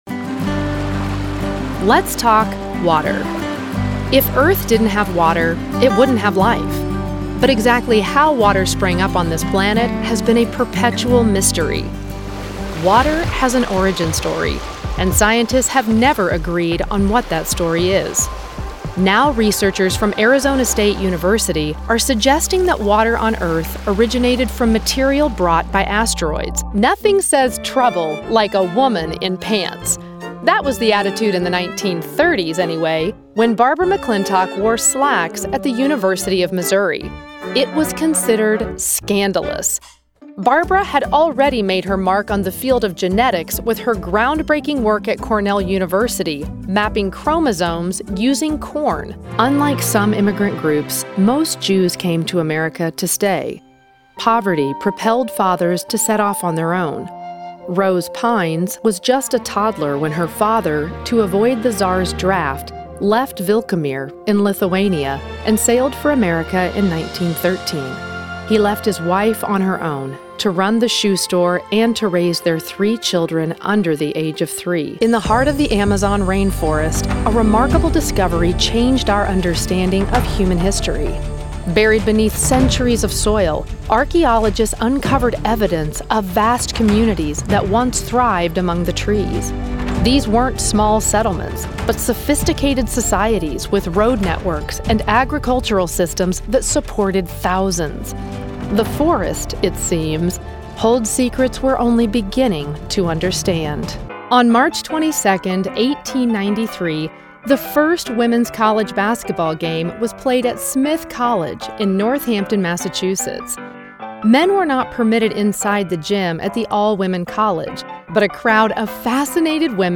Documentary Demo
General American, East Texas, Pacific Northwest, California, Southern, Character Brooklyn